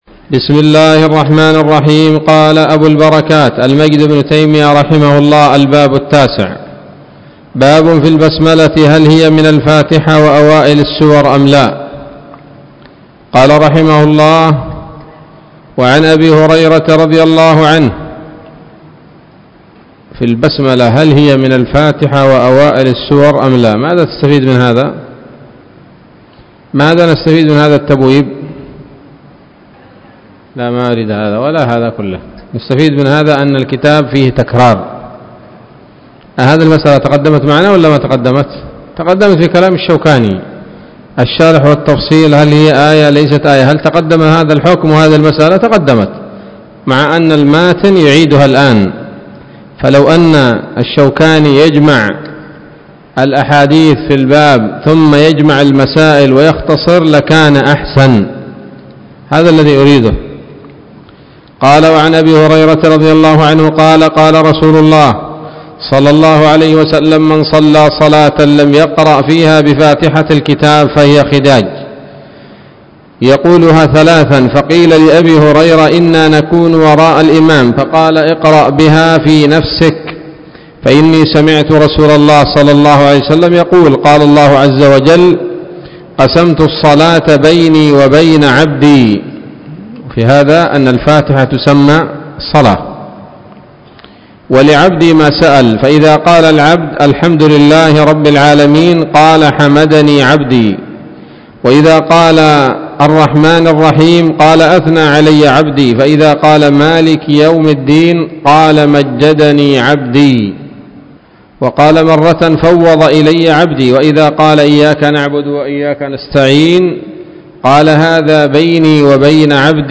الدرس السادس والعشرون من أبواب صفة الصلاة من نيل الأوطار